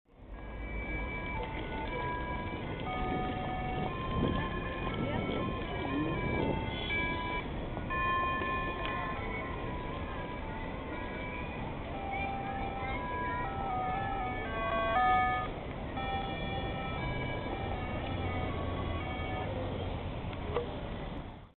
I recorded this sound clip crossing a busy intersection in Hirakata, Osaka, Japan.
Not to mention the tune's haunting use of the minor scale.
hirakata_shingu.mp3